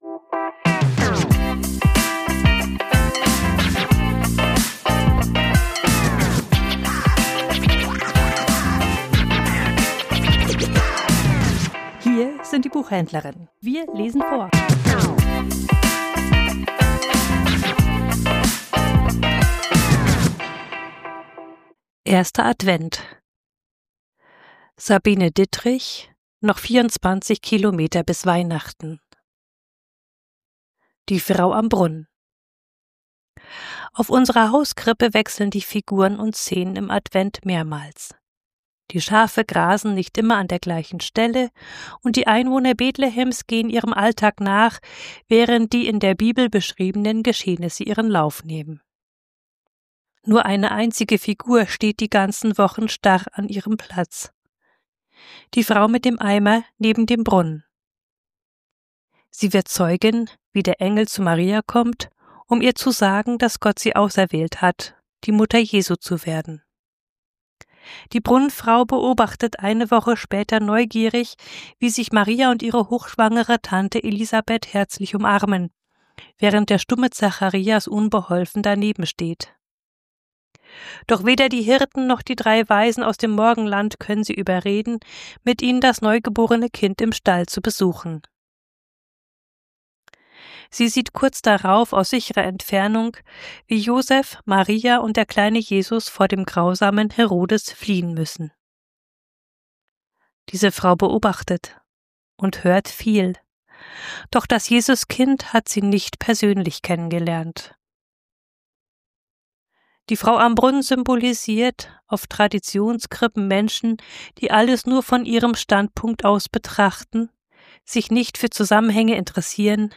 Vorgelesen: Die Frau am Brunnen